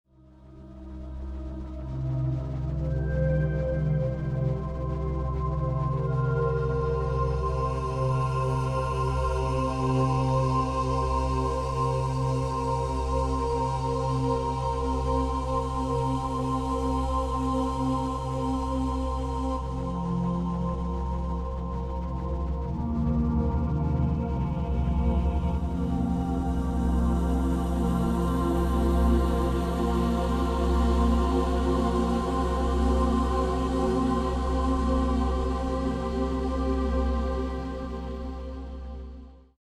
Category: deep ambient, experimental, soundscapes